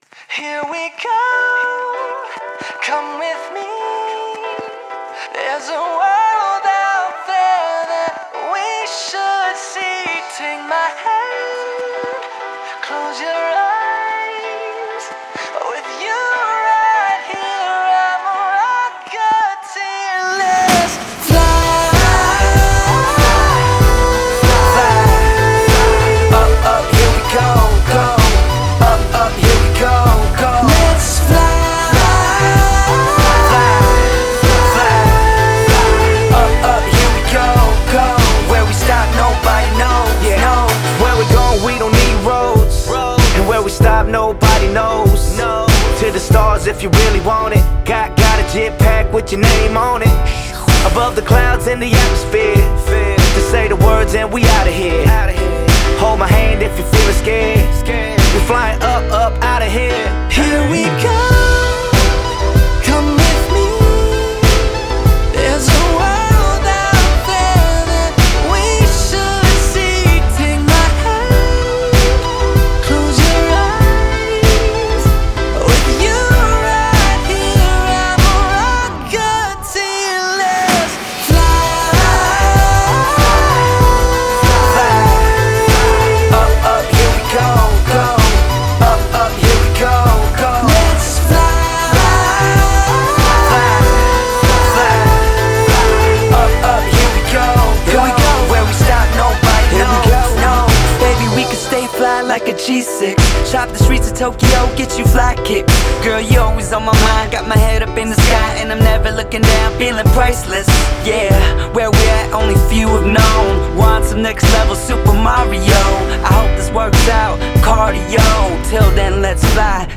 Категория: Зарубежный рэп, хип-хоп